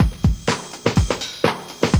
JUNGLEBRE04R.wav